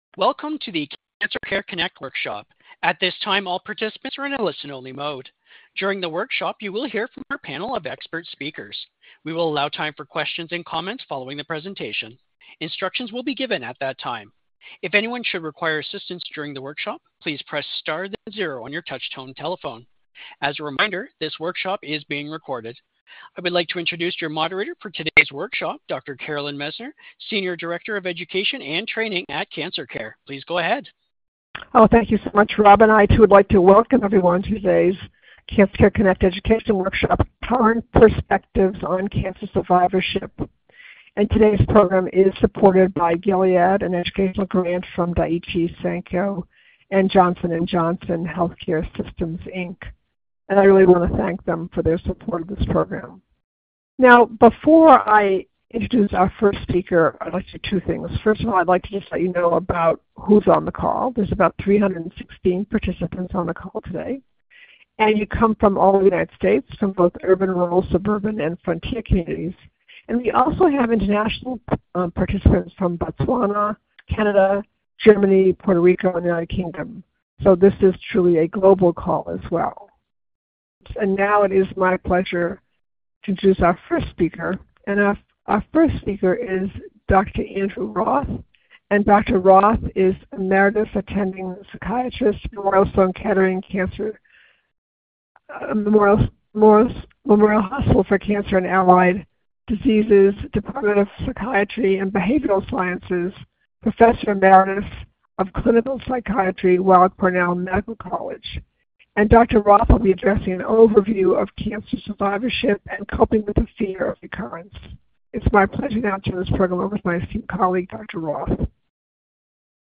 Questions for Our Panel of Experts
Workshop Date